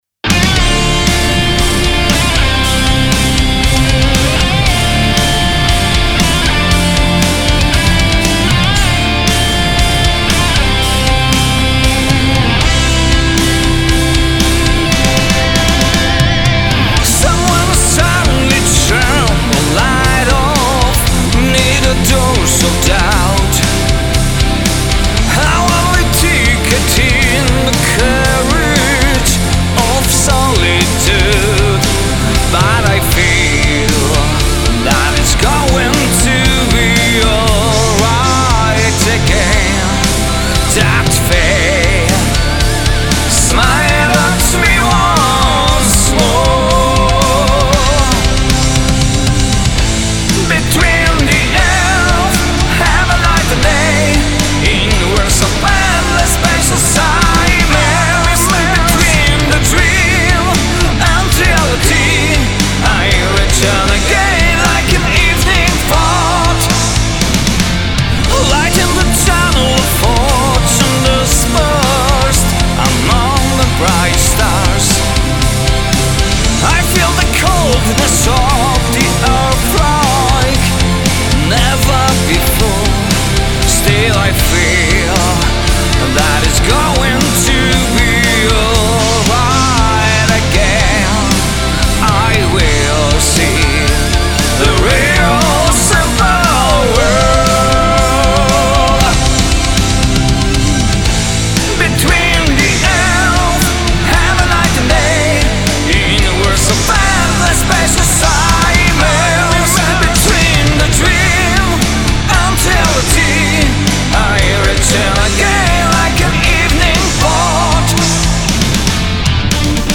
Gatunek: Power Metal